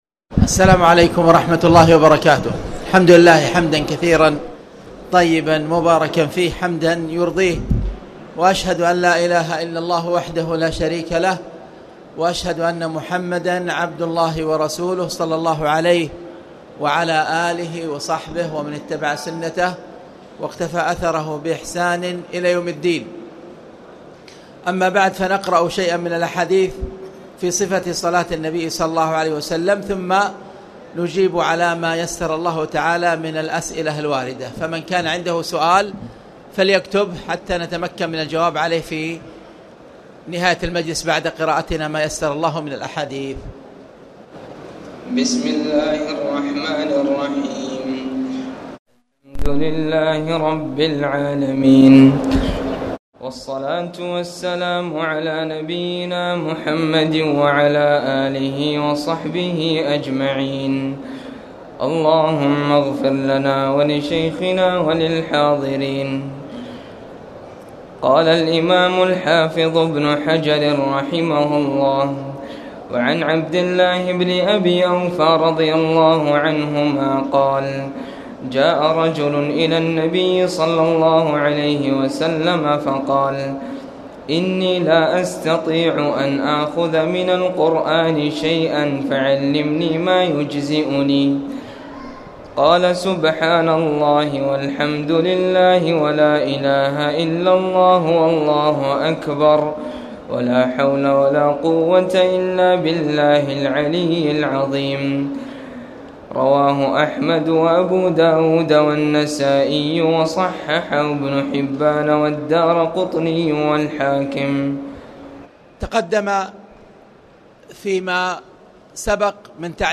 تاريخ النشر ١٢ رمضان ١٤٣٨ هـ المكان: المسجد الحرام الشيخ